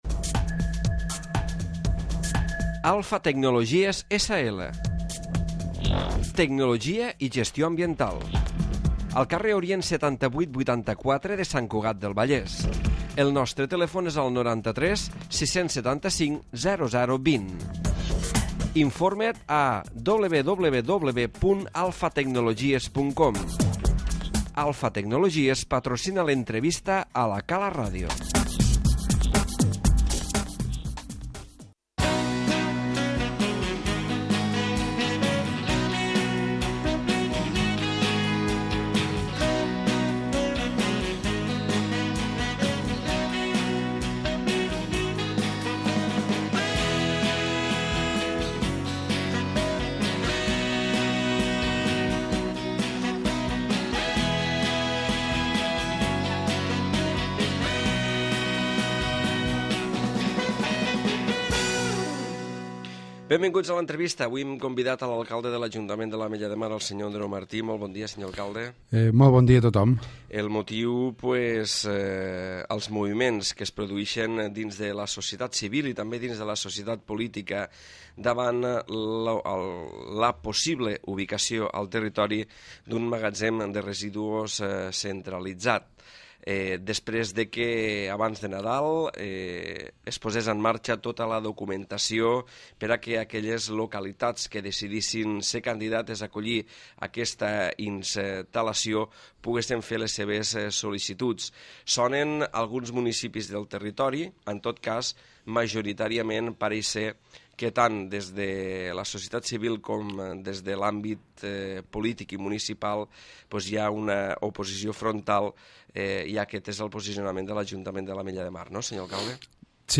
L'Entrevista
L'Alcalde Andreu Martí parla avui a l'entrevista sobre l'oposició frontal de l'Ajuntament de l'Ametlla de Mar a la instal·lació d'un magatzem centralitzat de residuus nuclears a les Terres de l'Ebre, sigui quina sigui la seva ubicació, a més de posar